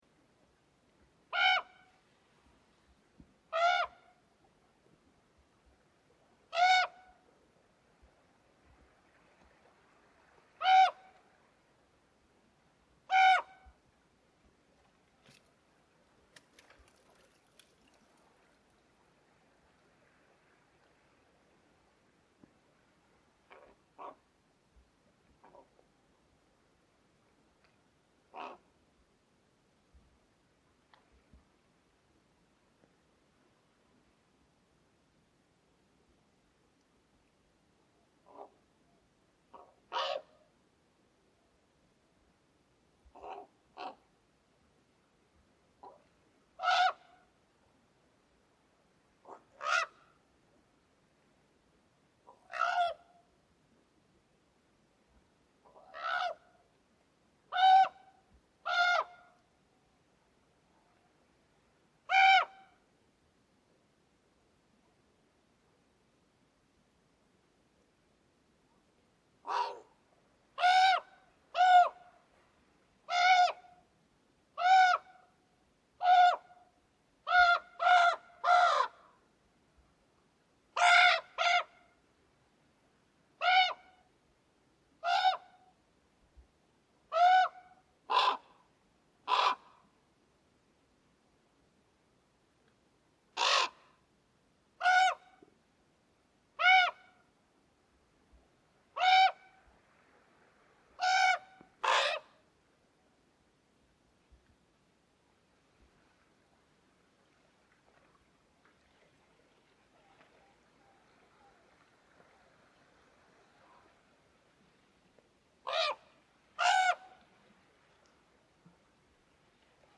Raven calls
They are one of the few birds that remain all winter, are unafraid of humans, and have a seemingly infinite variety of calls.
This recording was taken along the “ocean road” – one of the most beautiful walks in Sisimiut. This solo raven was perched above me for several minutes calling – if only I understood what he was saying...